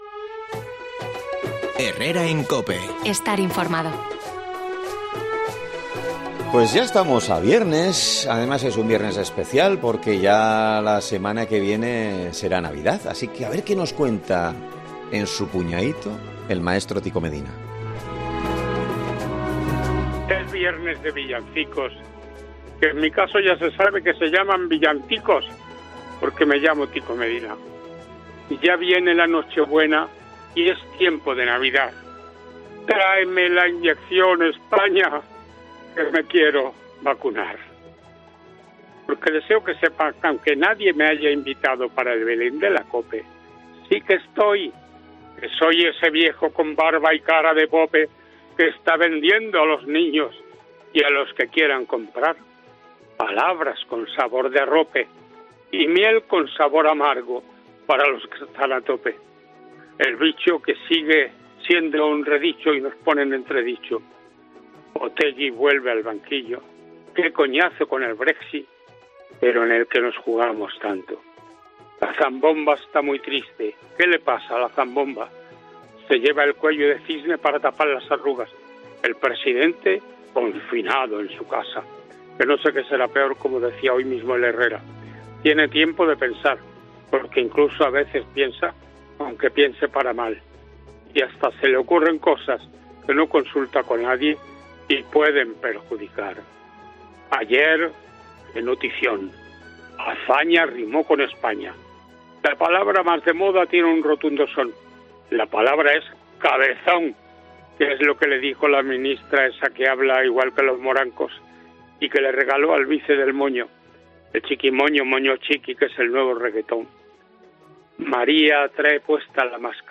El veterano periodista analiza la actualidad de la semana en su original 'puñaíto': que llegue la vacuna a España